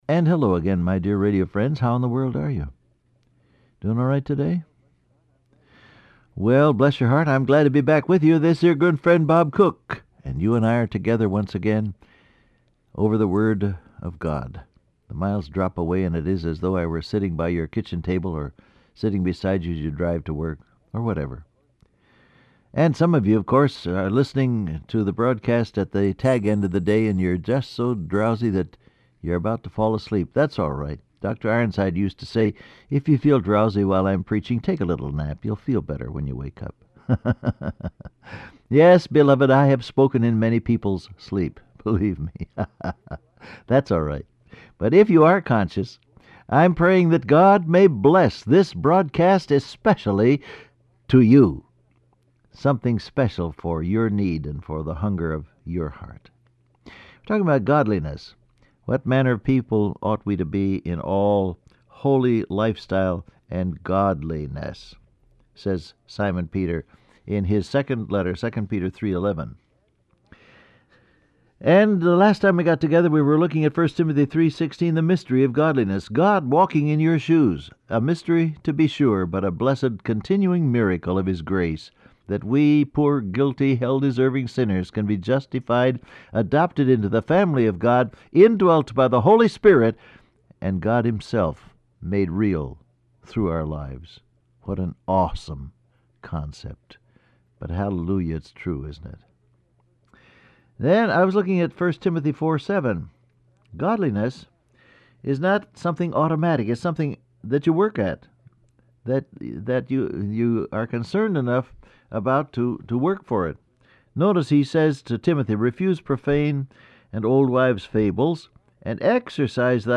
Download Audio Print Broadcast #7262 Scripture: 2 Peter 3:11 , 1 Timothy 4:7 Topics: Prayer , Godliness , The Word Of God Transcript Facebook Twitter WhatsApp And hello again my dear radio friends.